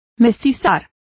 Complete with pronunciation of the translation of crossbred.